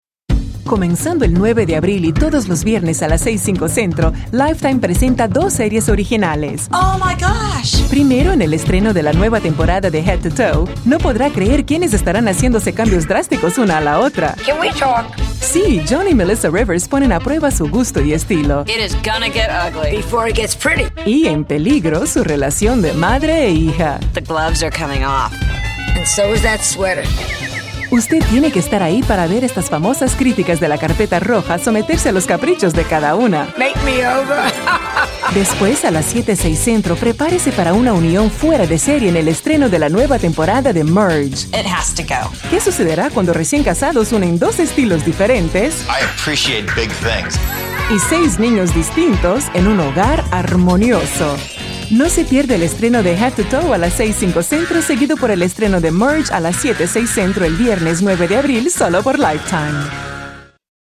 Mature Adult, Adult, Young Adult
Has Own Studio
Spanish - Latin American
corporate narration